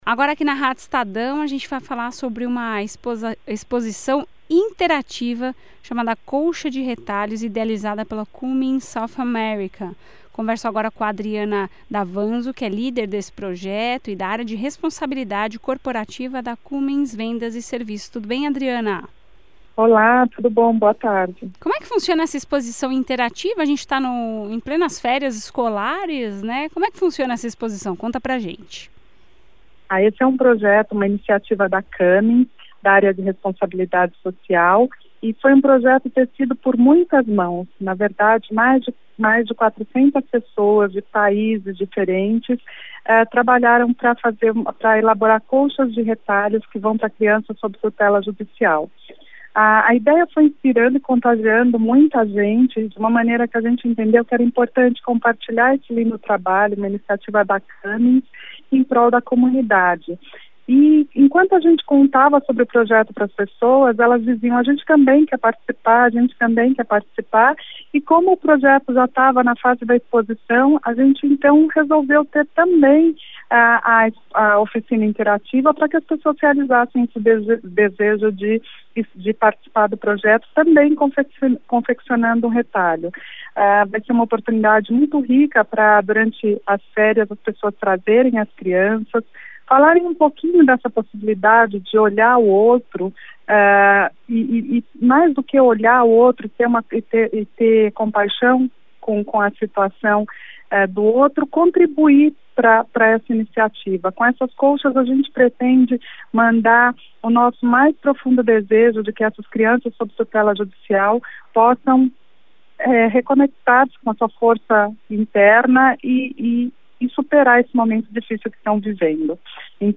Entrevista-Radio-Estadao-Colcha-Retalhos.mp3